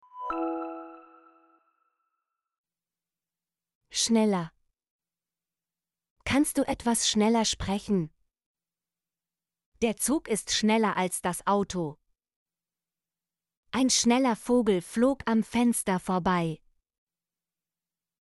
schneller - Example Sentences & Pronunciation, German Frequency List